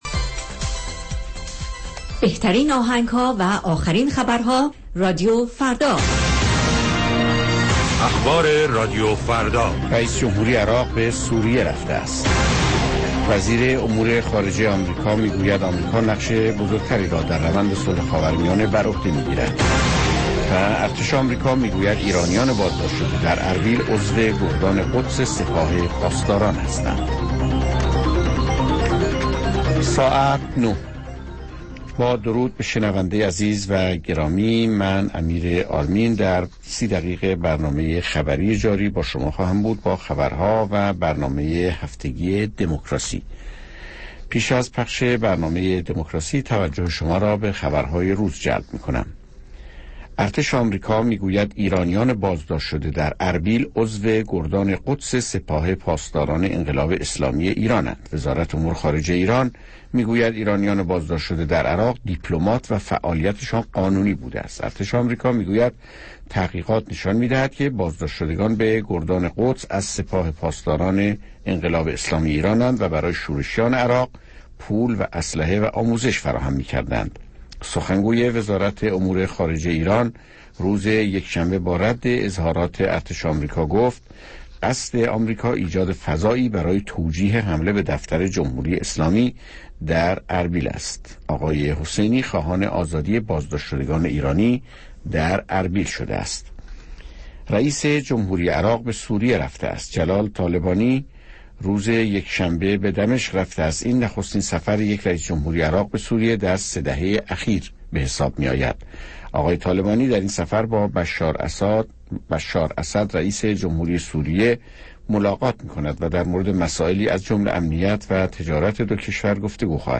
نیم ساعت با تازه ترین خبرها، گزارشهای دست اول در باره آخرین تحولات جهان و ایران از گزارشگران رادیوفردا در چهارگوشه جهان، گفتگوهای اختصاصی با چهره های خبرساز و کارشناسان، و مطالب شنیدنی از دنیای سیاست، اقتصاد، فرهنگ، دانش و ورزش.